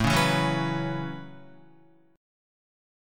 A6add9 chord